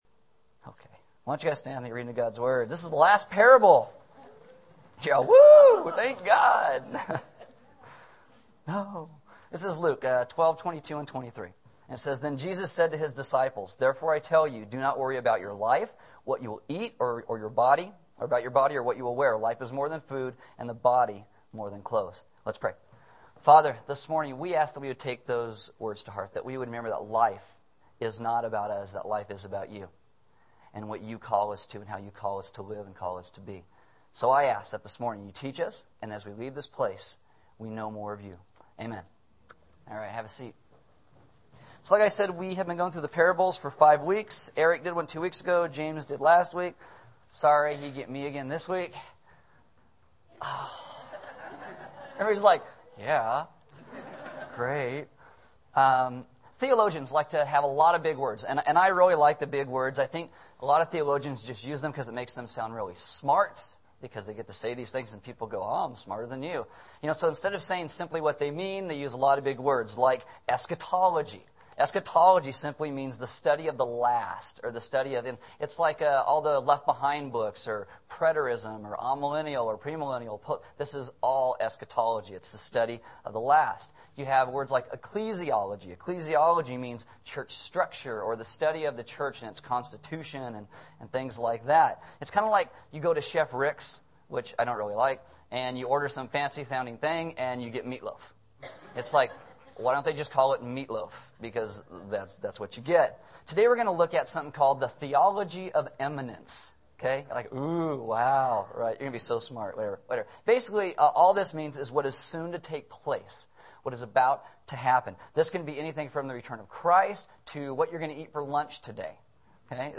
A message from the series "The Parables."